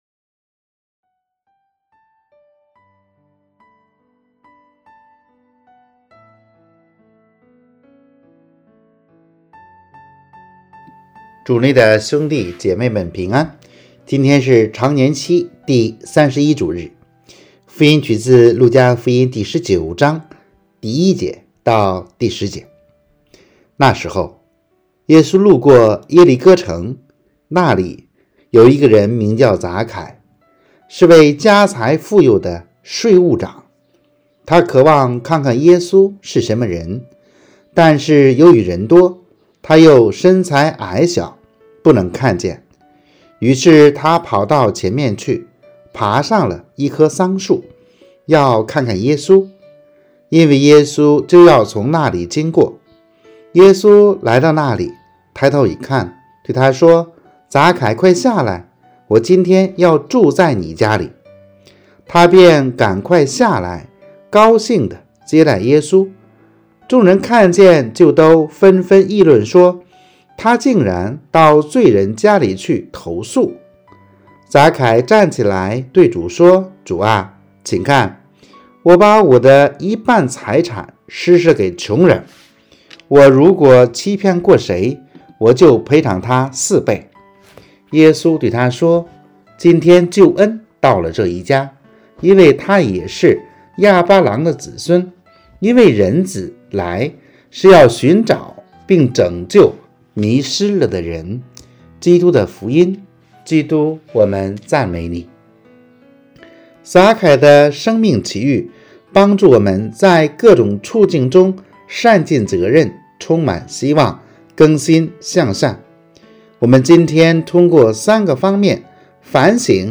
【主日证道】|好奇遇到天主（丙-常31主日）